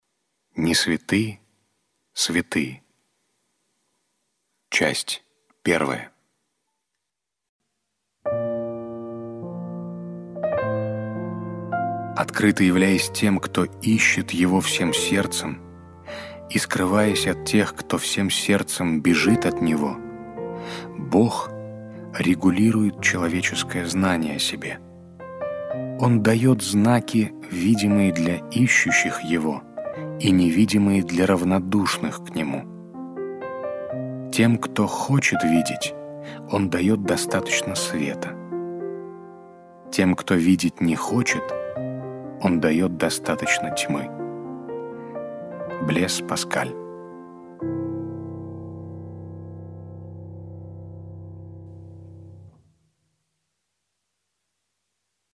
Аудиокнига «Несвятые святые» и другие рассказы | Библиотека аудиокниг